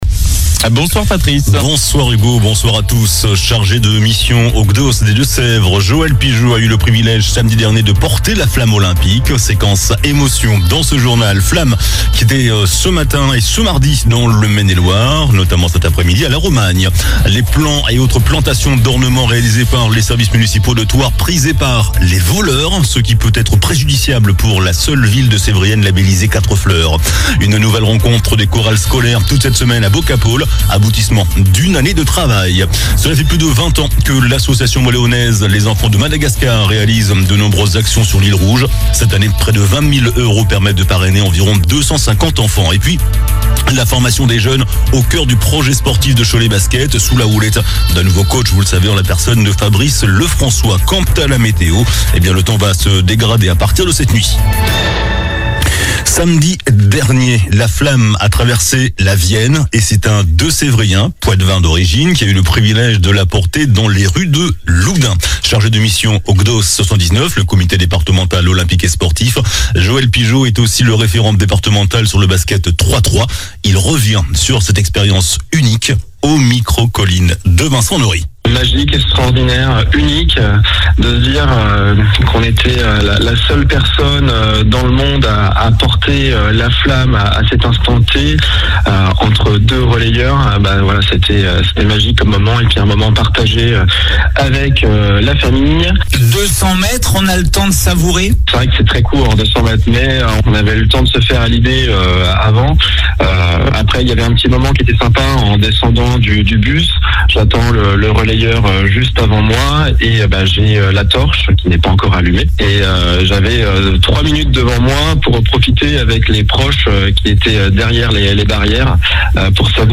JOURNAL DU MARDI 28 MAI ( SOIR )